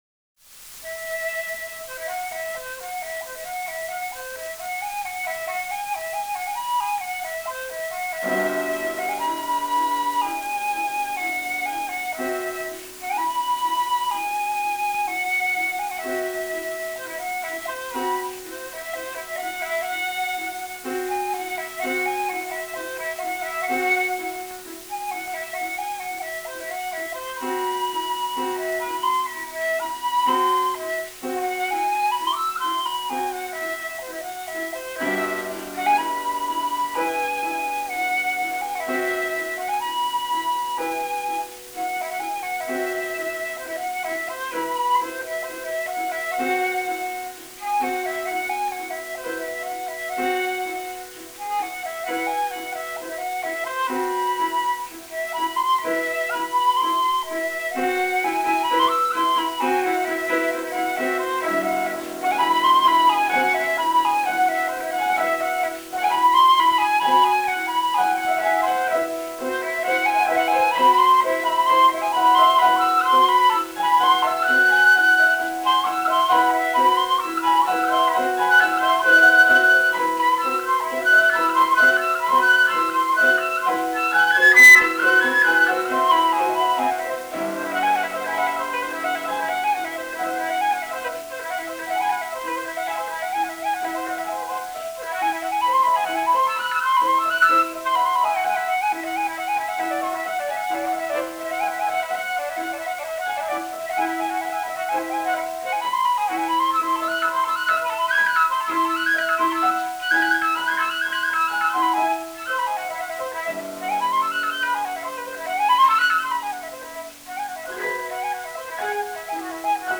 playing a transcription of the gamelan piece